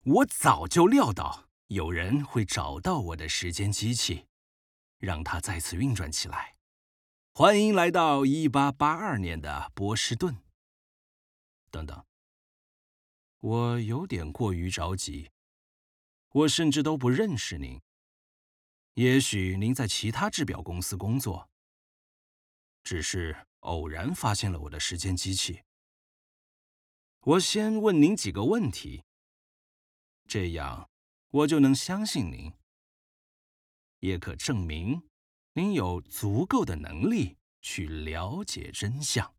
Kommerziell, Junge, Natürlich, Freundlich, Warm
Persönlichkeiten